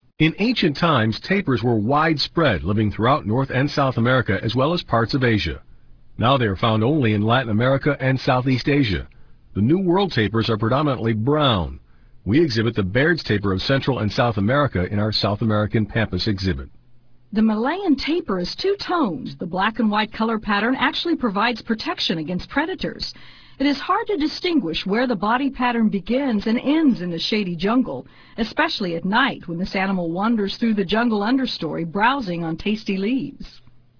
Bairds Tapier
bairdstapir_sound.ram